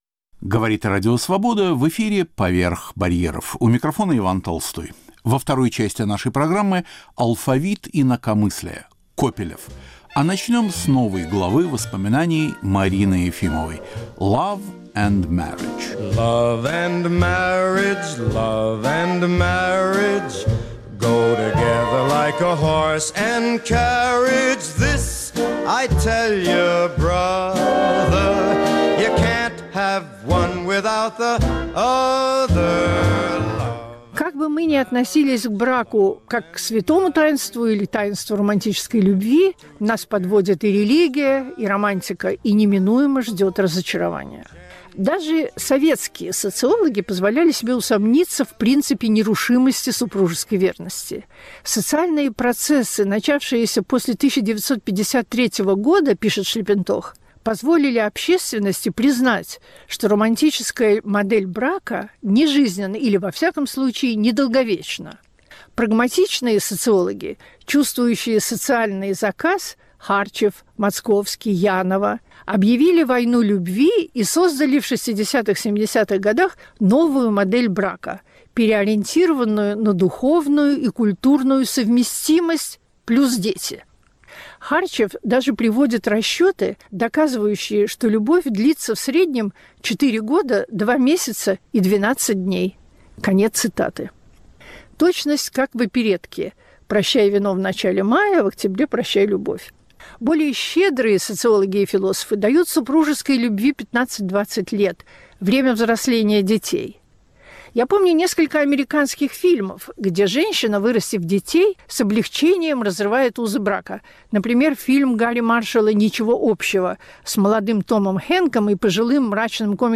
В первой части - выпуск "Алфавита инакомыслия". Разговор о Льве Копелеве - литературоведе, переводчике, заключенном шарашки, активном общественном деятеле.